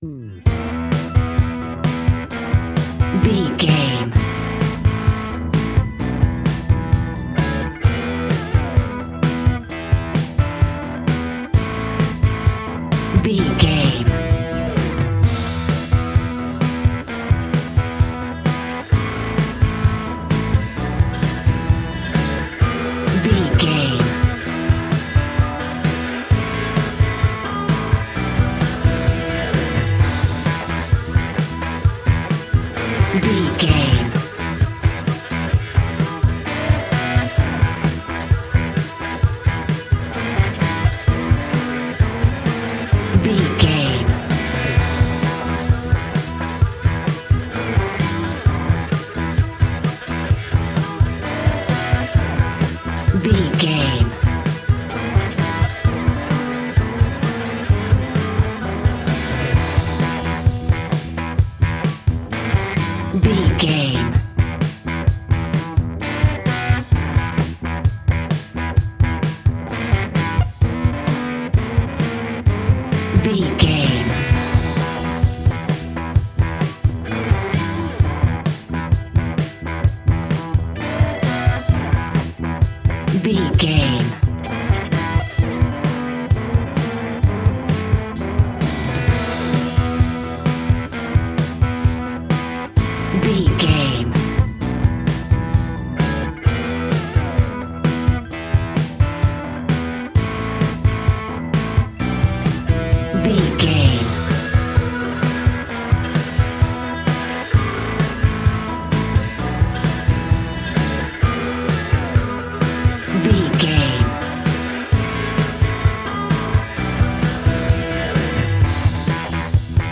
Ionian/Major
E♭
hard rock
blues rock
distortion